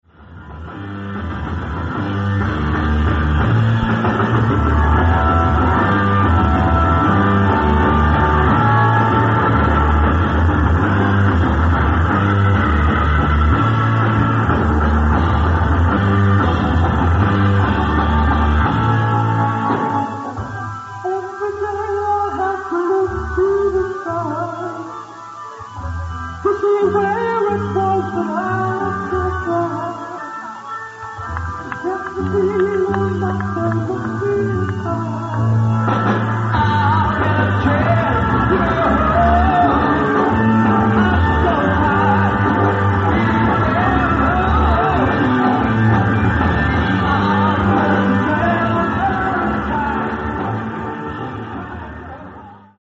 Sound:  Remastered
Source:  Audience